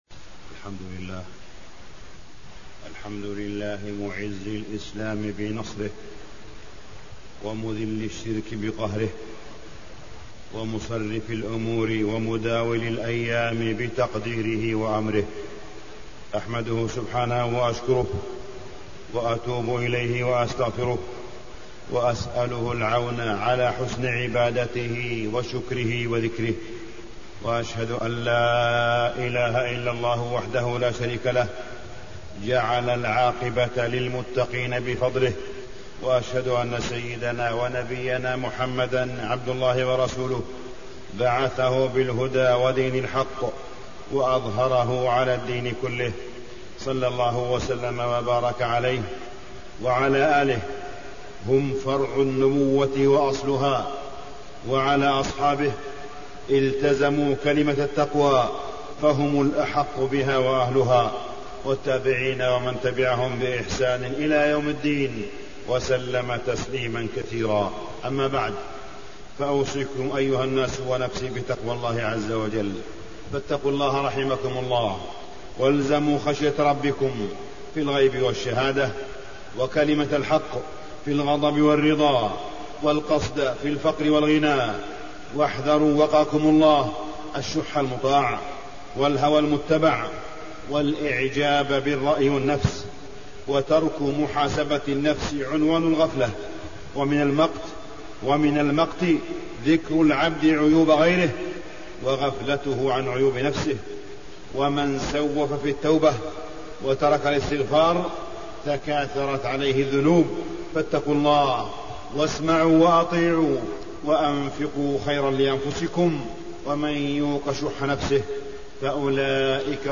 تاريخ النشر ٢ جمادى الأولى ١٤٣١ هـ المكان: المسجد الحرام الشيخ: معالي الشيخ أ.د. صالح بن عبدالله بن حميد معالي الشيخ أ.د. صالح بن عبدالله بن حميد بيت المقدس